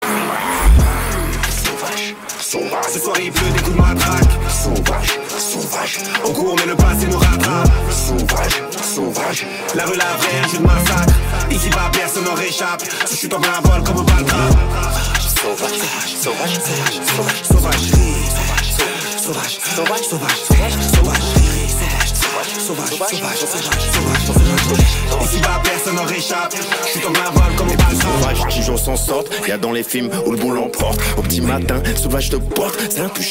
Catégorie Rap / Hip Hop